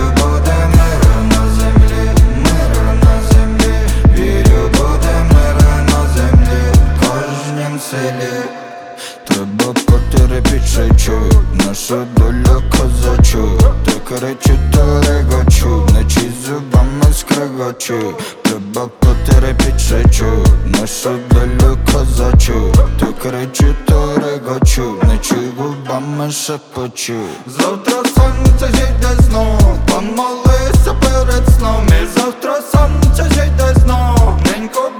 Жанр: Рэп и хип-хоп / Иностранный рэп и хип-хоп / Украинские